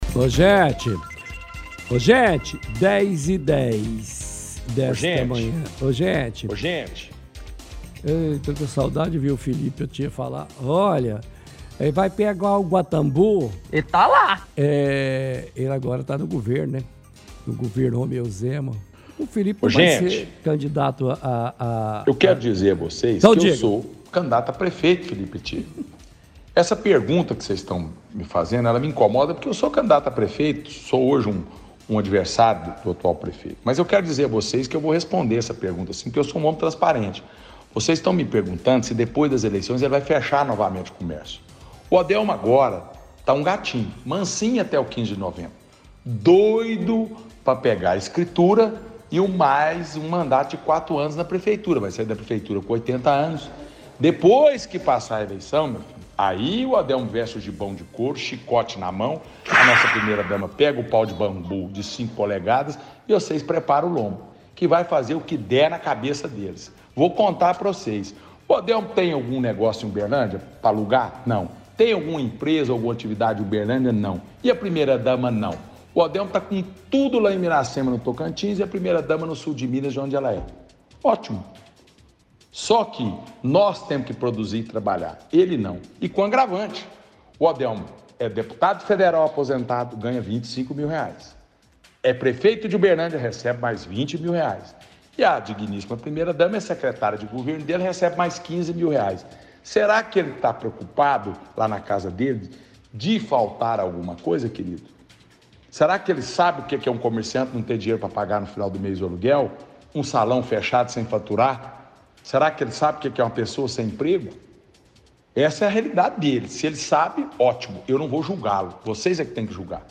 – Transmissão de áudio antigo de Felipe Attiê dizendo que o prefeito faria o que quisesse depois que ganhasse as eleições.